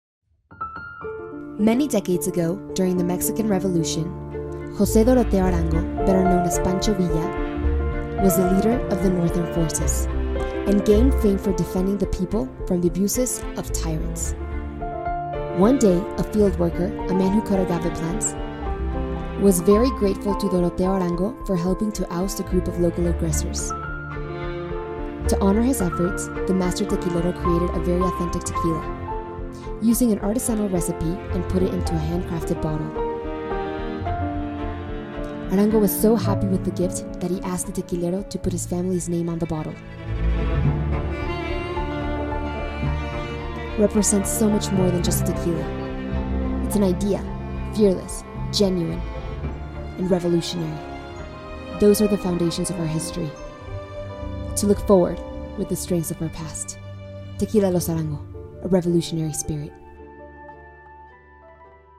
Dokumentarfilme
Wenn Sie eine jugendliche Stimme sowohl für Englisch als auch für Spanisch suchen, werden Sie es nicht bereuen, meine für Ihr Projekt ausgewählt zu haben!
Home Studio, Rode NT1 Kondensatormikrofon der 5. Generation